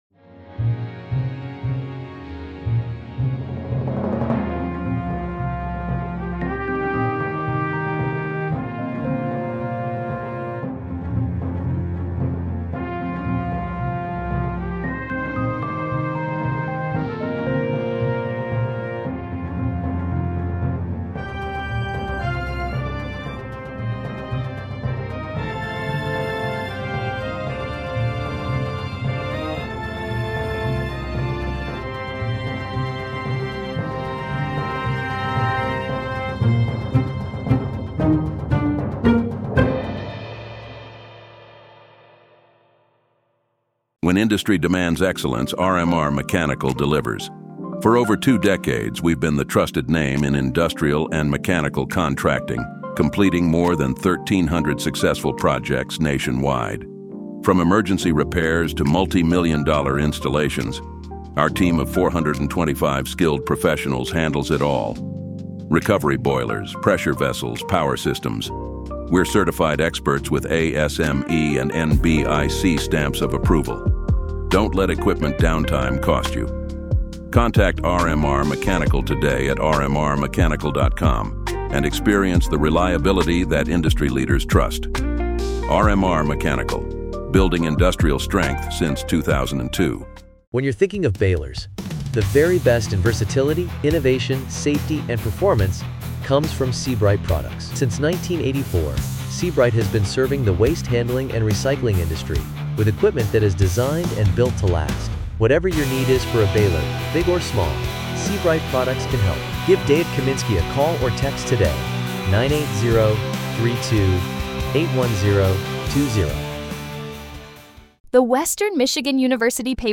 Interview conducted by Paperitalo